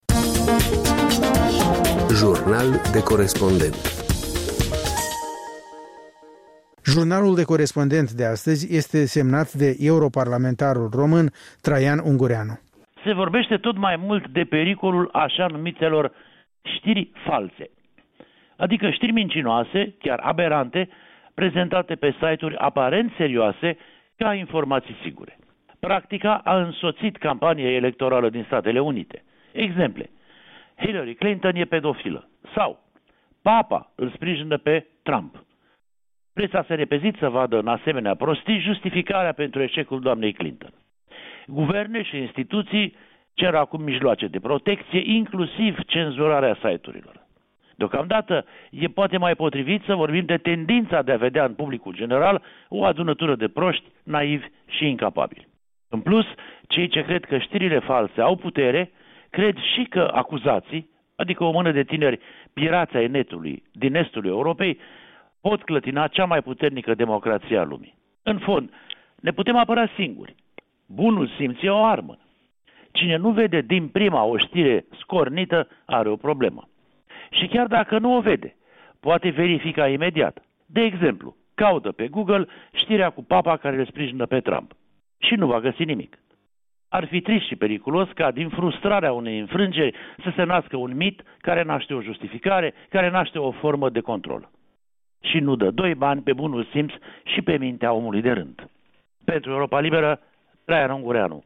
Jurnal de corespondent